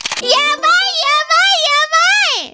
Worms speechbanks
CollectArm.wav